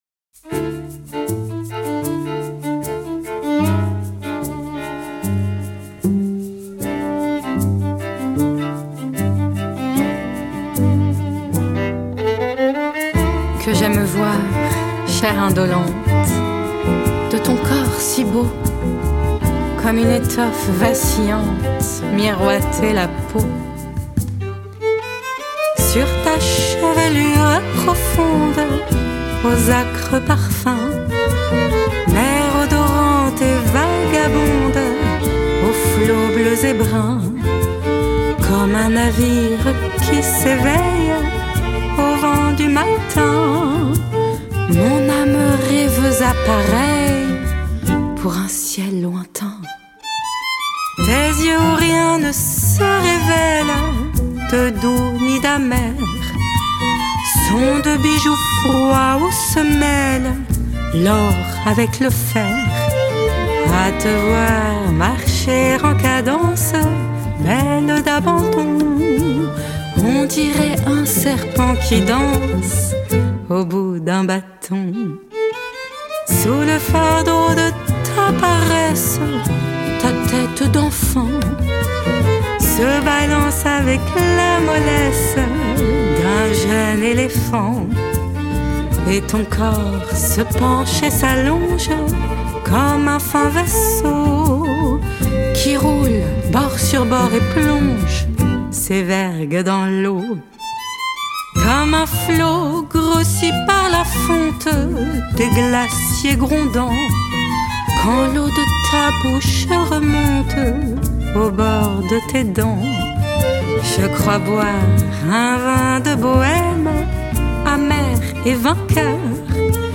pianiste